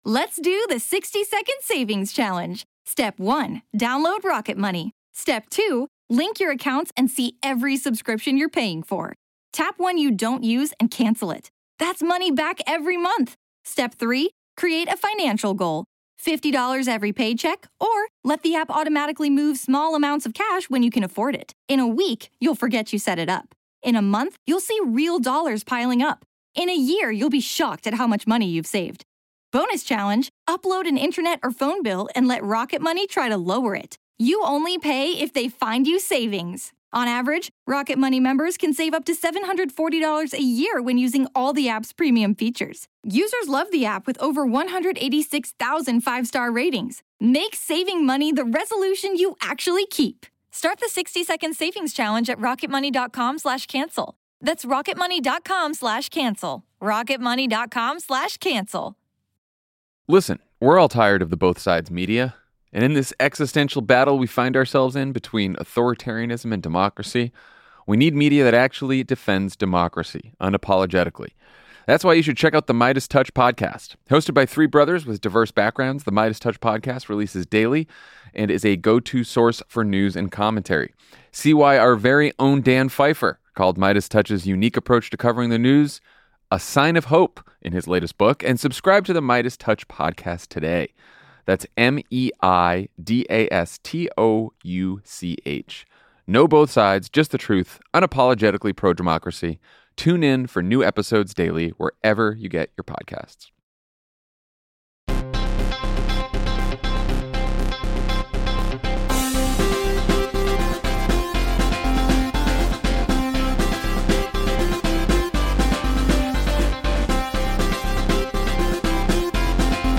The Republican presidential candidates and Joe Biden battle with each other over abortion a year after the Dobbs decision. The president road tests a new economic message and gets advice about his age from Hollywood. Texas Congressman Colin Allred stops by to talk about his Senate race against Ted Cruz.